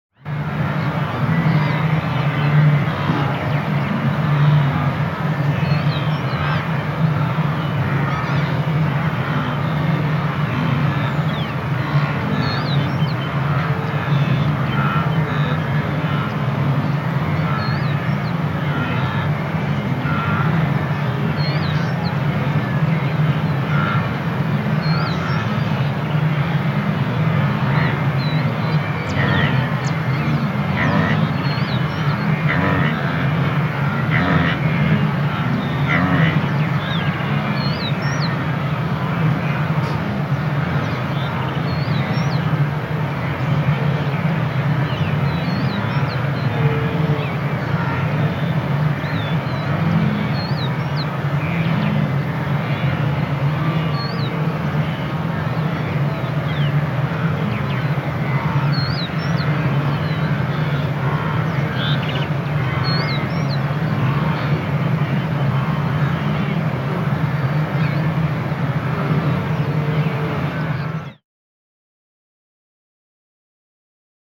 دانلود صدای گاو وحشی 8 از ساعد نیوز با لینک مستقیم و کیفیت بالا
جلوه های صوتی